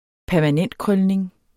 Udtale [ -ˌkʁœlneŋ ]